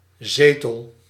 Ääntäminen
IPA: /ze.tǝl/